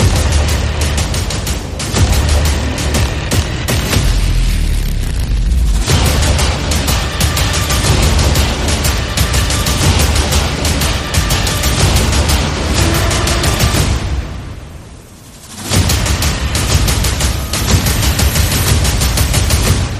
energetic